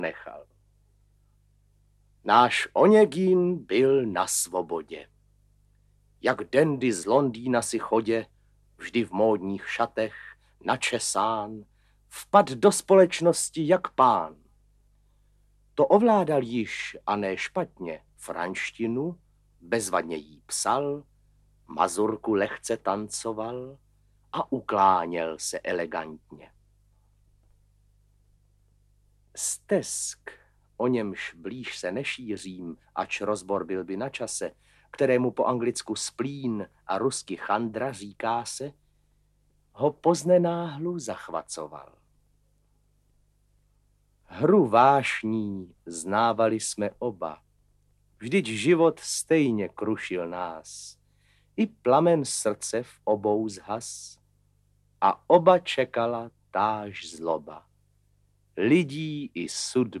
Audiobook
Audiobooks » Poetry, Classic Works